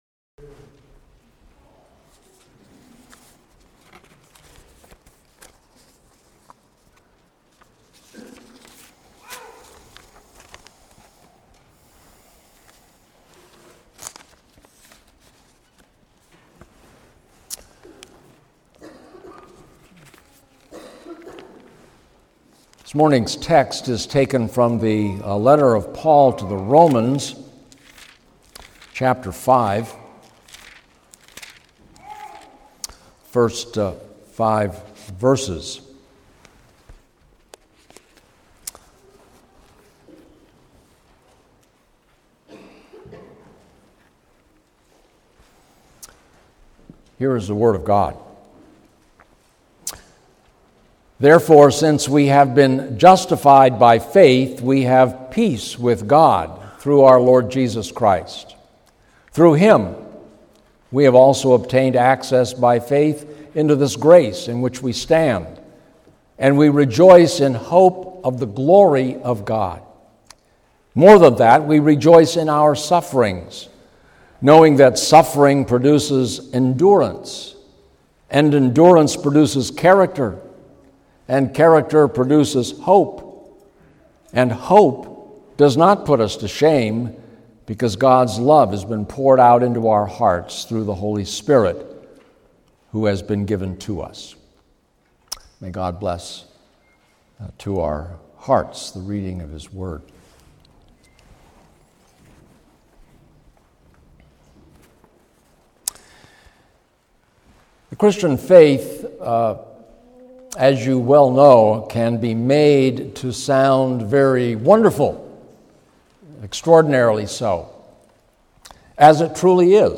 Romans — Browse Sermons | WPC